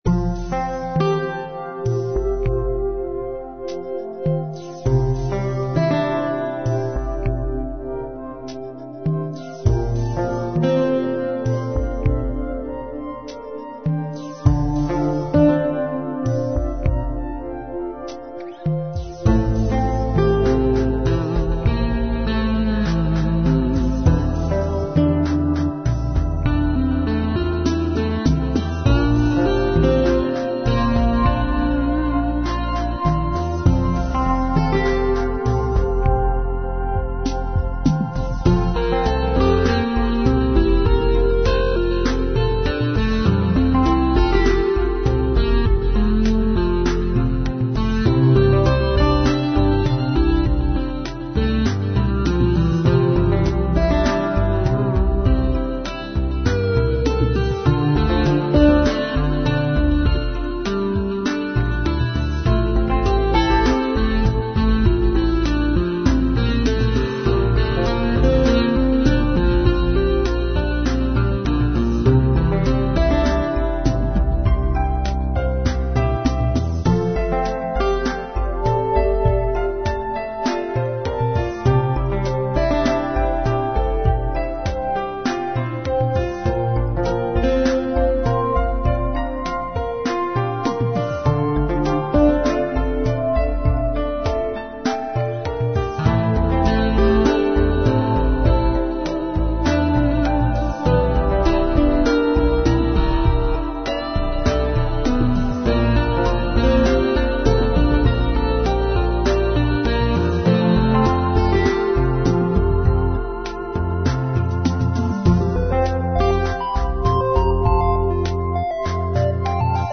Slow dreamy Electro Chillout